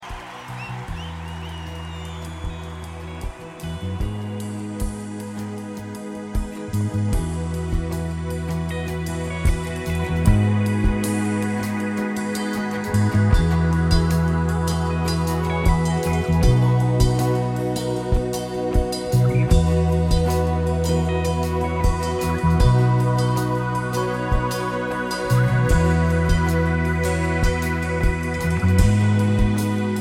Live Worship...
• Sachgebiet: Praise & Worship